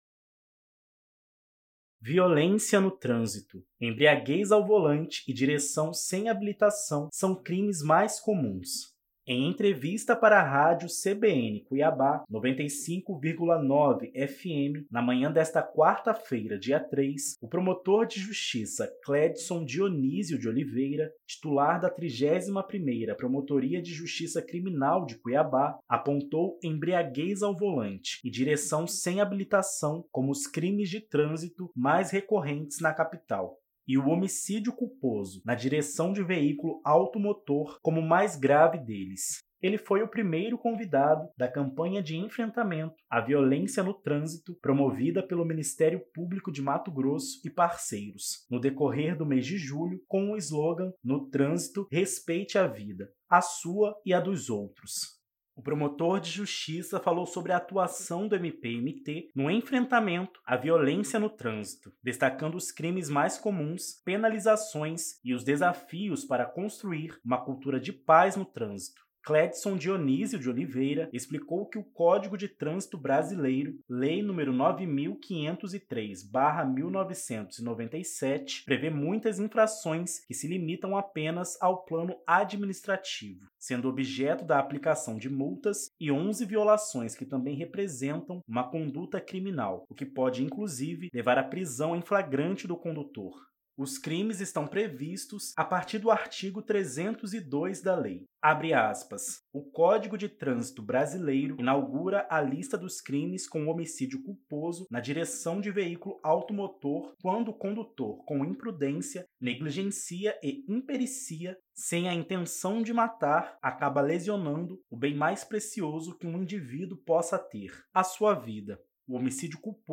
O entrevistado falou sobre a evolução do CTB.